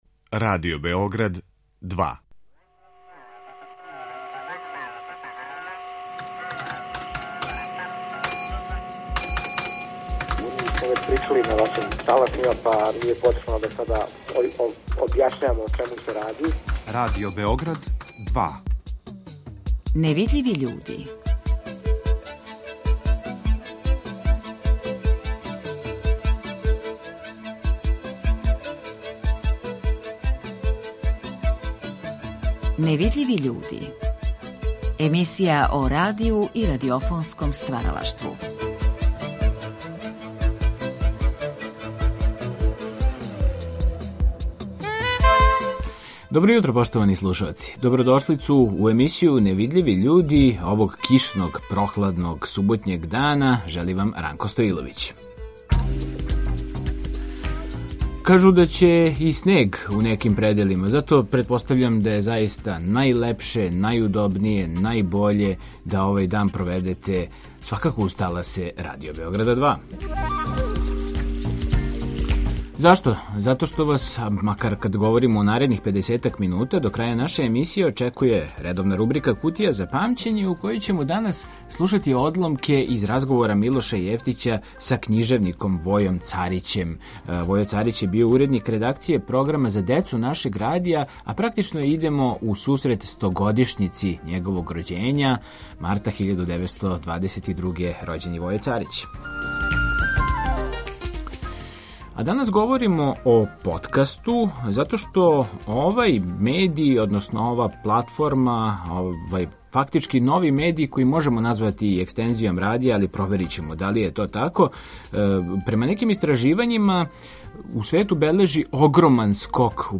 Са разних тачака земље и света, у емисији Невидљиви људи говоре аутори успешних подкаста